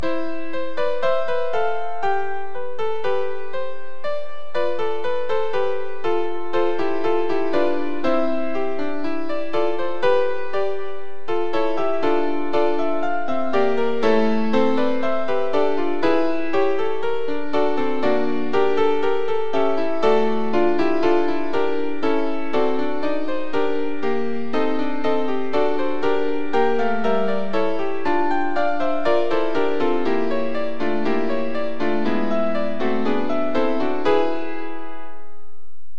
- Adoramus te (Women)